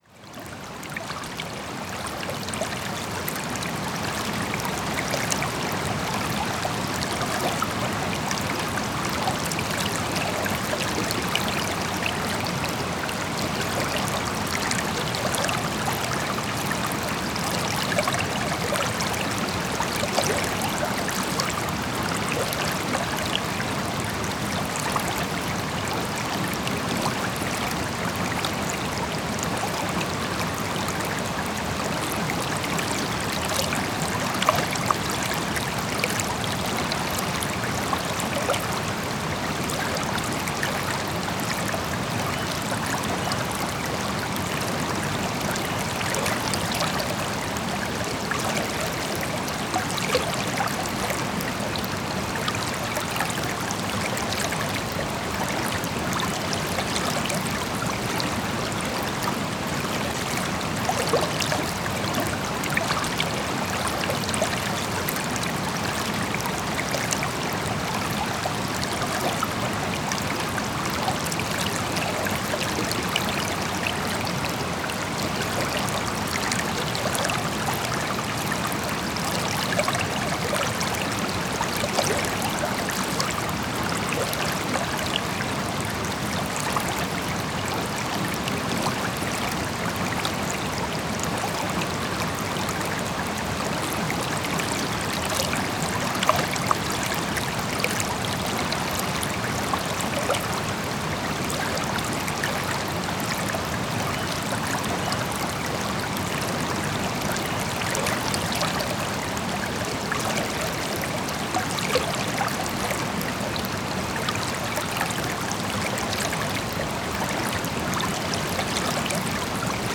Ruisseau apaisant de montagne (sans musique) pour sommeil profond, méditation, relaxation
Se détendre, déstresser ou s'endormir avec le bruit apaisant d'un ruisseau de montagne
Sons de la nature - avec plus de 4 heures de sons ambiants, enregistrés partout dans le monde.